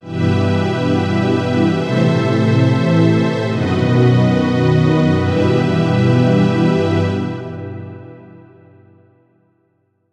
oder auch eine Imitation eines realen Instruments sein:
string_imitation.mp3